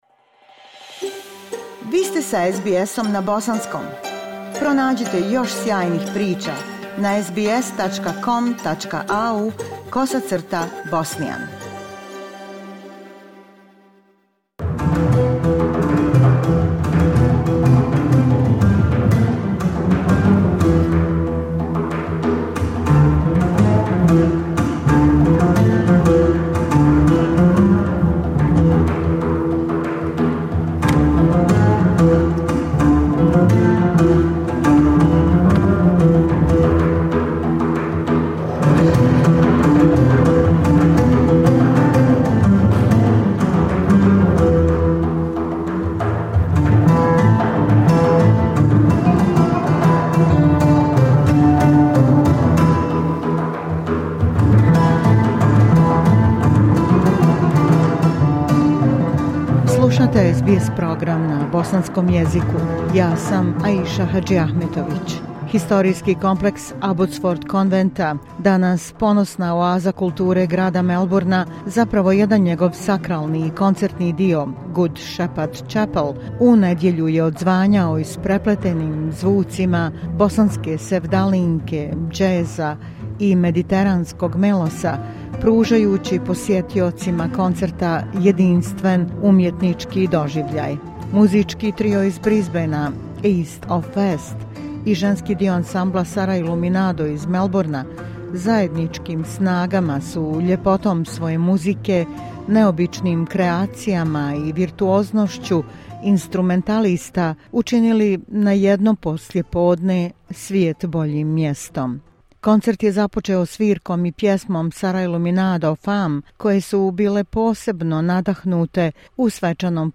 Abbotsford Convent odzvanjao prepletenim zvucima sevdalinke i jazza
Muzički trio iz Brisbanea "East of West" i ženski dio ansambla Saray Illuminado iz Melbournea, zajedničkim snagama su ljepotom svoje muzike, neobičnim interpretacijama i virtuoznošću instrumentalista učinili na jedno poslijepodne, svijet boljim mjestom.